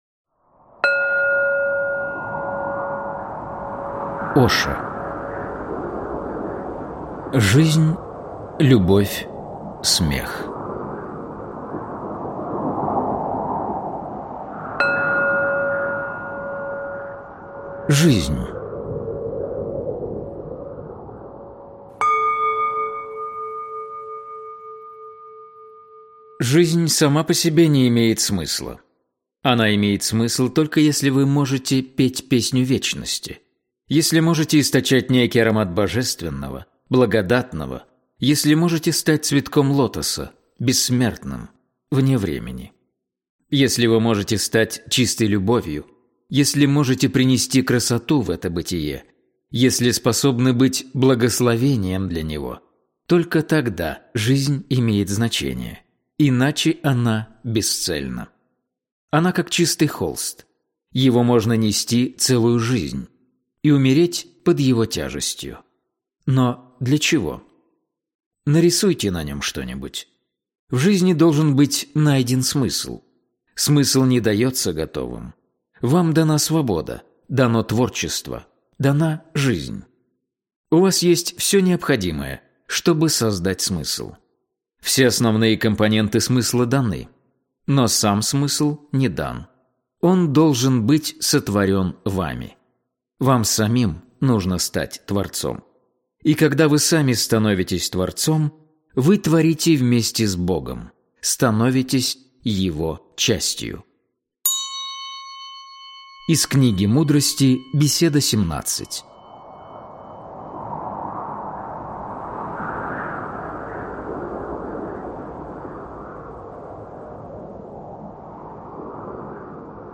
Aудиокнига Жизнь, любовь, смех.
Читает аудиокнигу